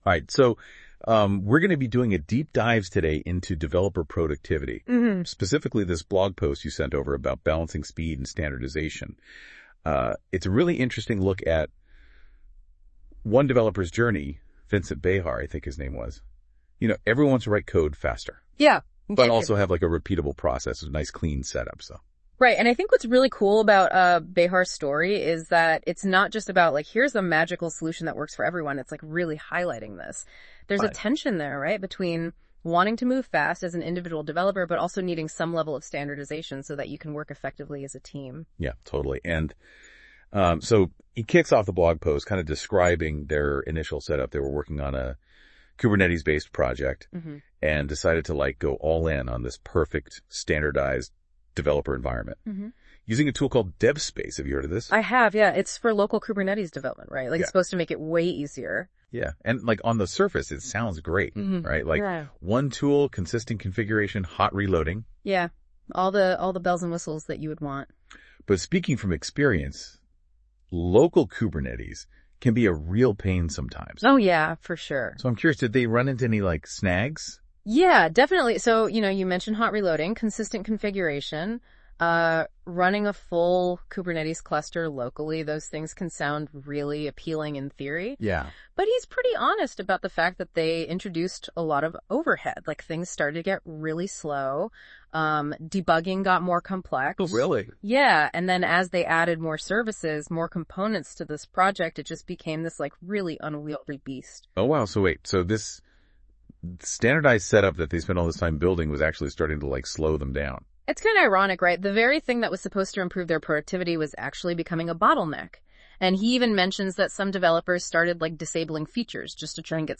AI-generated audio podcast - 7 minutes long - generated by NotebookLM.